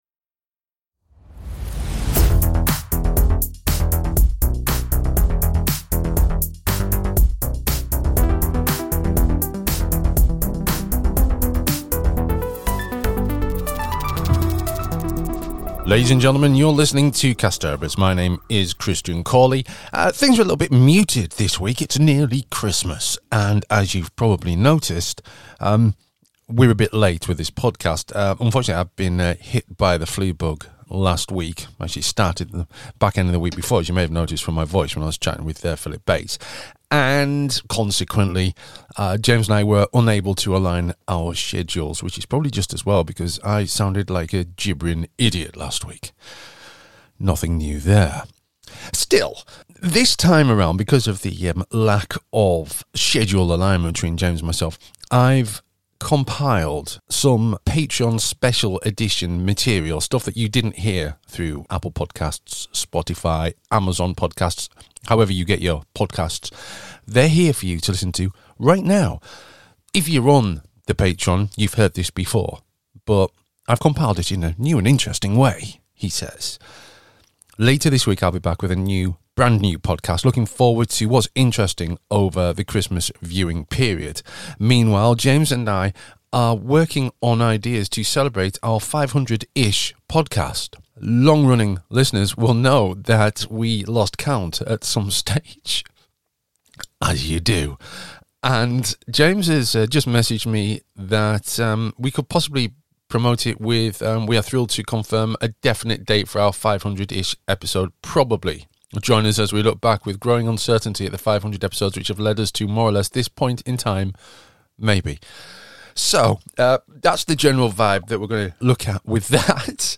This week's Doctor Who podcast is a collection of chats that were held back for Patreon members.